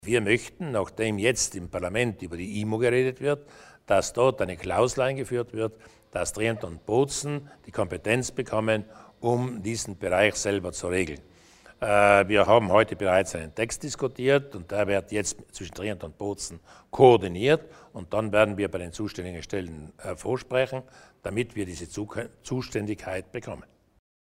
Landeshauptmann Durnwalder über die Kompetenzen in Sachen Lokalfinanzen